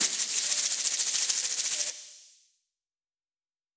serpiente.wav